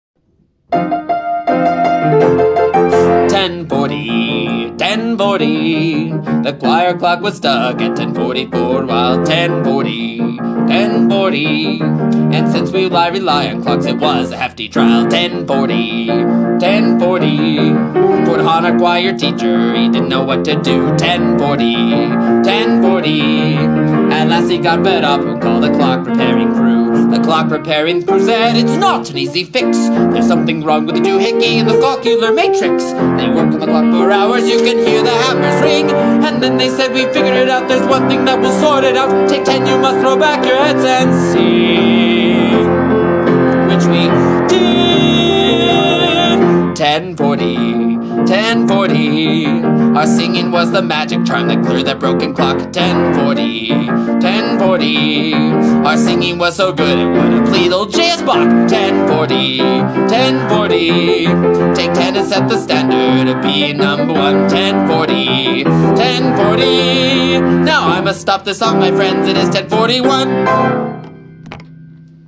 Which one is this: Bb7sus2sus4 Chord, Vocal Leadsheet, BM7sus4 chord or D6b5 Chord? Vocal Leadsheet